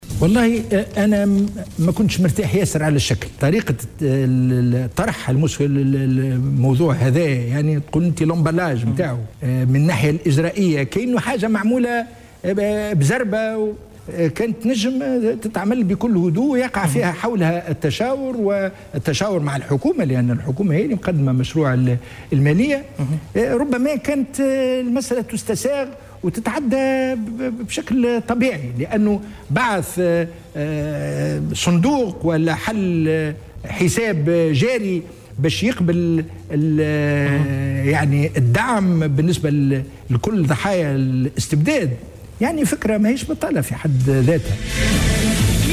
أكد رئيس المجلس الوطني التأسيسي،مصطفى بن جعفر خلال لقاء تلفزي ضرورة إرساء حكومة مستقلة والابتعاد عن التجاذبات السياسية لتكون حكومة مهدي جمعة المقبلة قادرة على تحقيق الأفضل، وفق تعبيره.